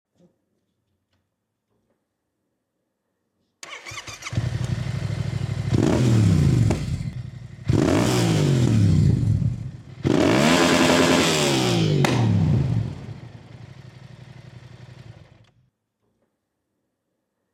Sound on 🔊 Yamaha R7 sound effects free download
Sound on 🔊 Yamaha R7 and Akrapovic Exaust